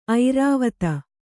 ♪ airāvata